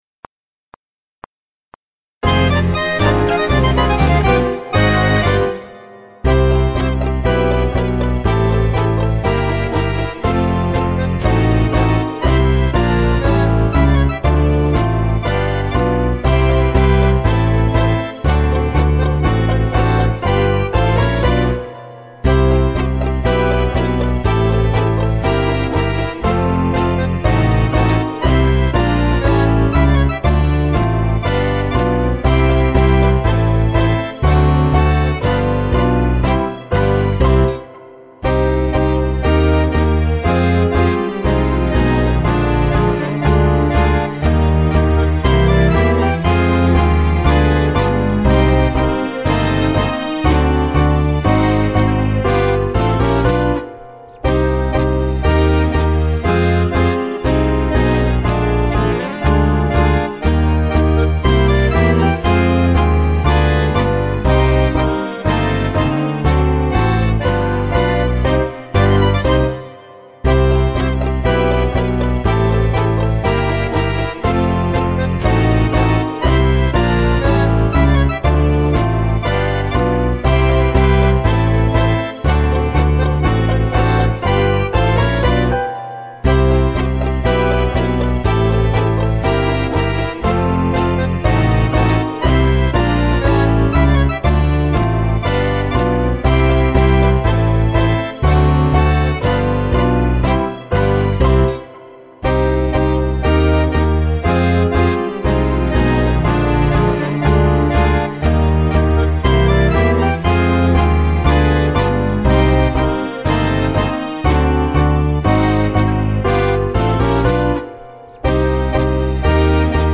LE PLAY BACK
rythmique